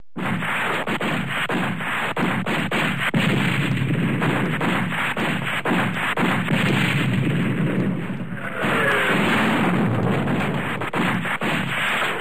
Ambient war sounds